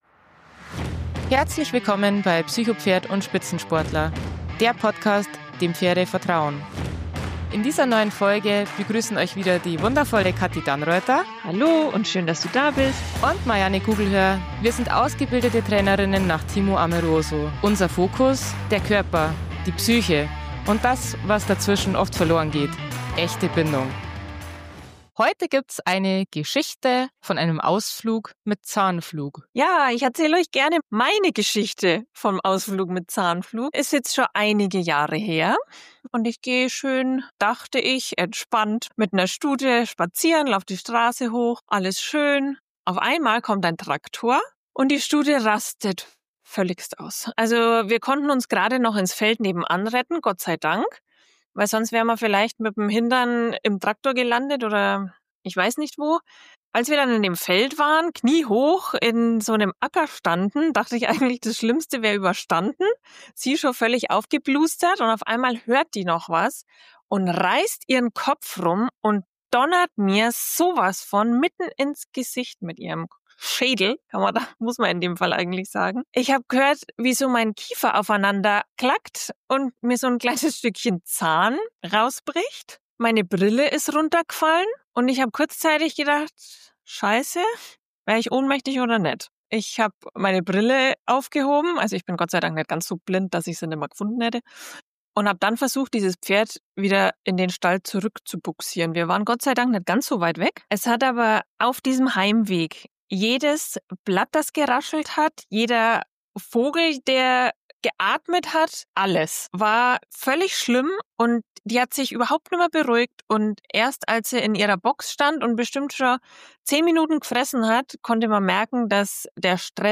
Freut euch auf ein ehrliches, humorvolles und tiefgehendes Gespräch, das euch neue Impulse für den Umgang mit euren Pferden – und auch mit euch selbst – geben wird.